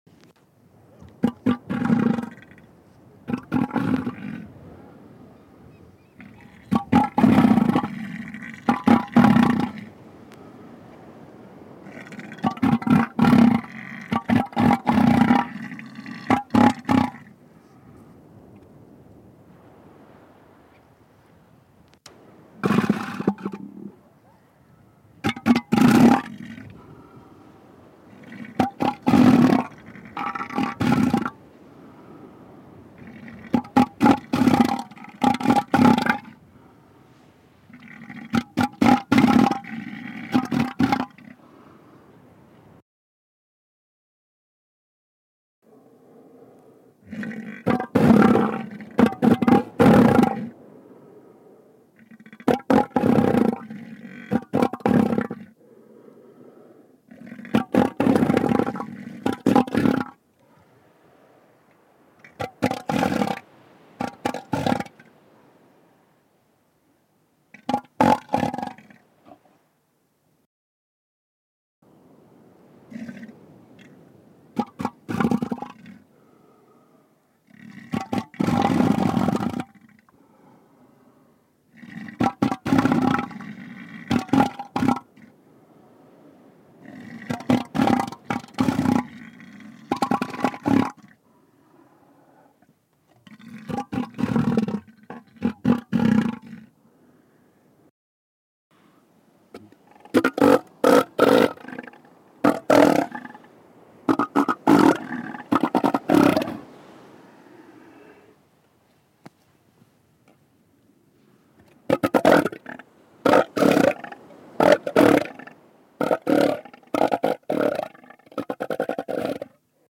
На этой странице собраны разнообразные звуки морских львов – от их громкого рычания до игривого плеска в воде.
Звук морского льва когда он ворчит